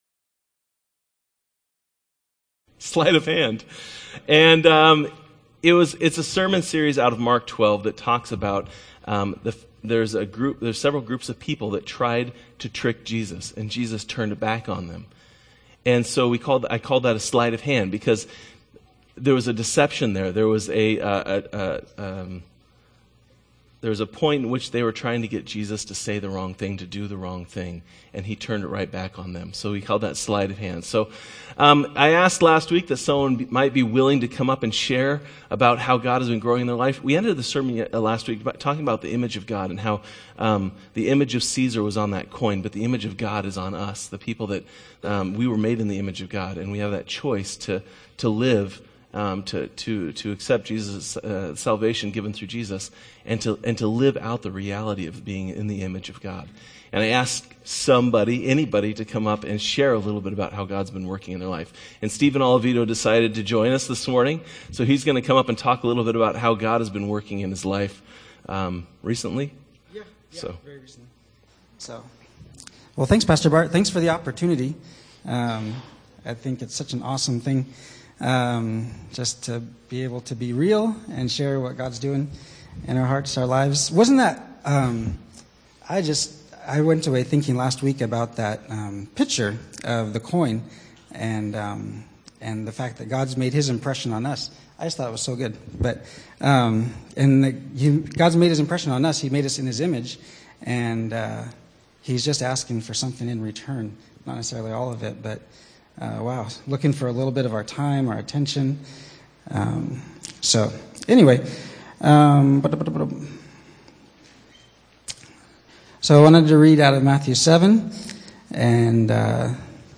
2015 Sermons